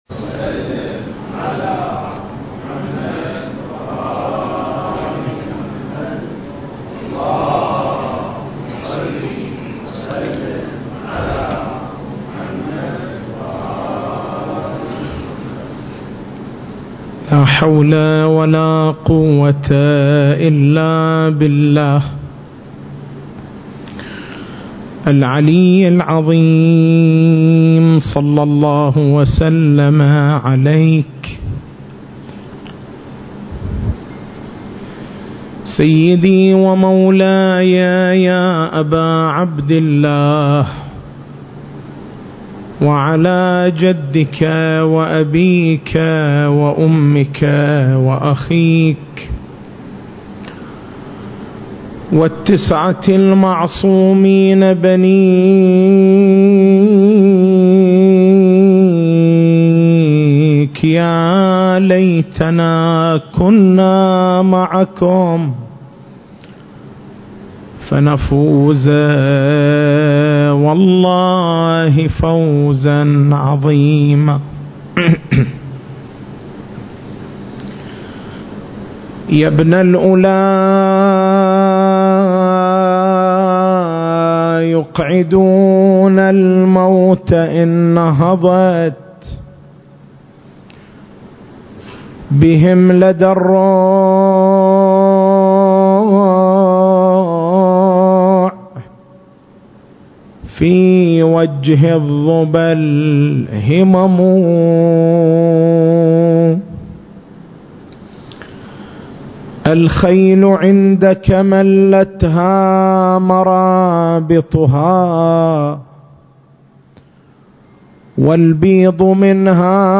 سلسلة: ولادة الإمام المهدي (عجّل الله فرجه) فوق التشكيك (1) نقاط البحث: - موقعية القضية المهدوية في المنظومة العقائدية - هل ولادة الإمام (عجّل الله فرجه) قضية عقائدية، أم هي قضية تاريخية؟ المكان: حسينية الحاج حبيب العمران التاريخ: 1440 للهجرة